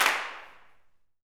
CLAPSUTC2.wav